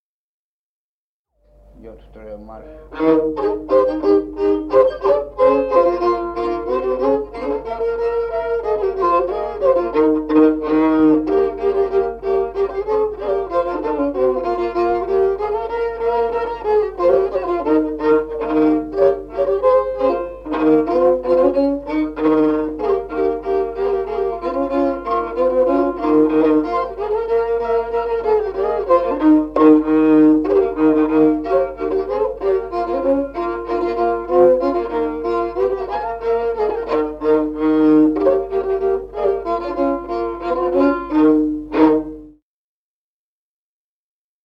Музыкальный фольклор села Мишковка «Марш, идут к венцу», партия 2-й скрипки.